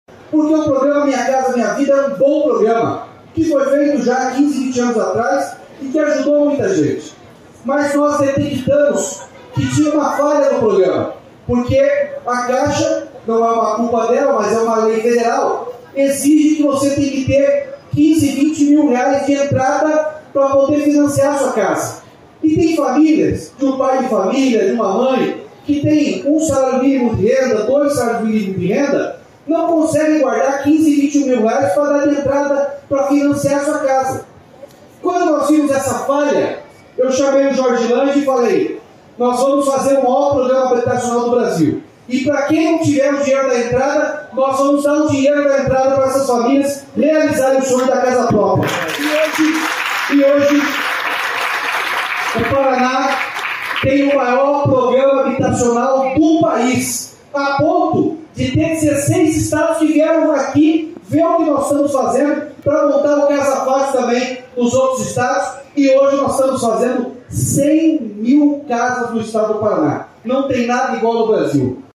Sonora do governador Ratinho Junior sobre a inauguração de bairro planejado com 371 casas em Assis Chateubriand